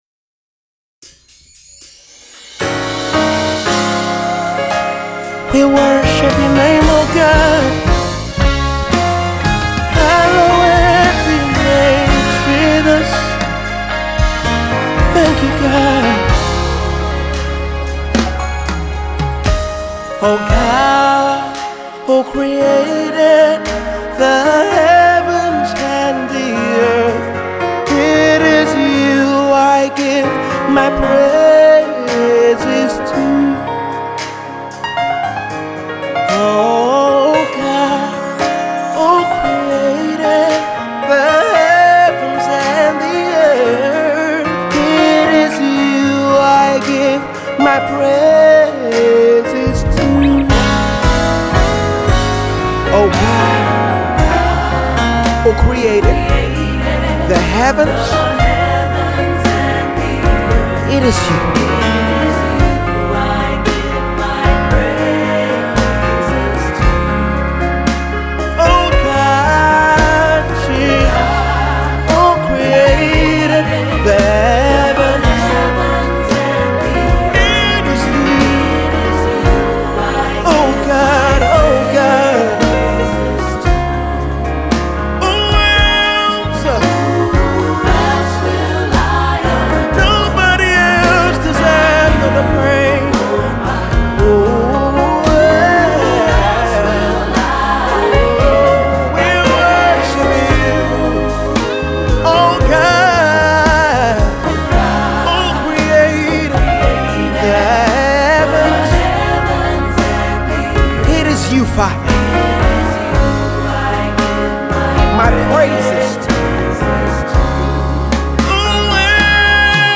An expression of worship-praise extolling God’s greatness
a simple ballad